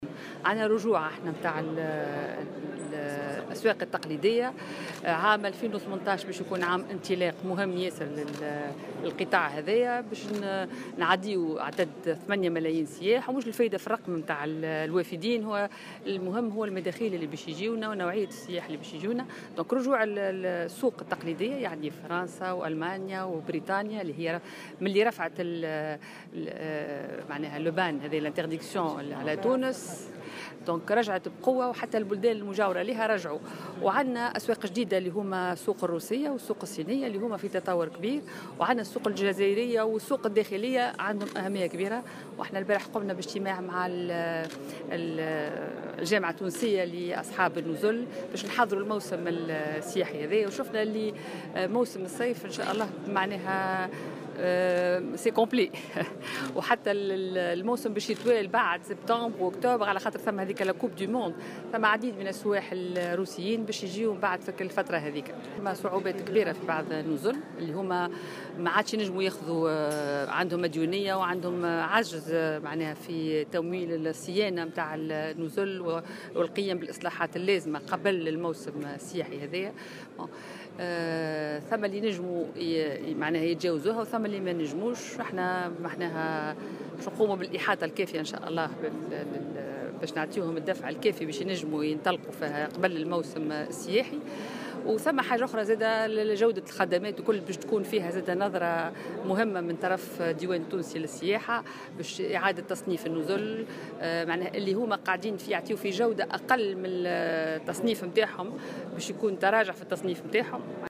وأضافت اللومي في تصريح لمراسل "الجوهرة أف أم" على هامش افتتاح القطب التنموي للزربية والمنسوجات، أنه تم تسجيل عودة للأسواق التقليدية على غرار السوق الفرنسية والألمانية والبريطانية، إضافة إلى تطور أسواق جديدة ( الروسية والصينية)، مشيرة إلى أن حجوزات الموسم الحالي بلغت الـ100 %.وتحدث اللومي أيضا عن إعادة تصنيف النزل التونسية وفق معايير جديدة، تأخذ بعين الاعتبار نوعية الخدمات المقدمة.